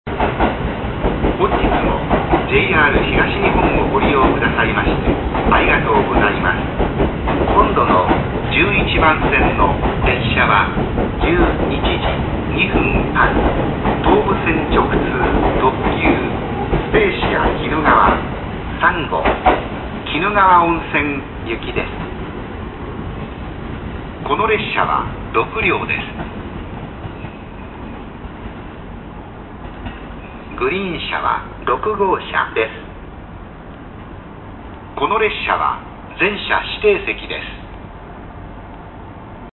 次発放送「スペーシアきぬがわ3号」 「スペーシアきぬがわ3号」の次発放送です。
常磐線では、「特別急行」と言ってましたが宇都宮線では「特急」と言い回しが変わりました。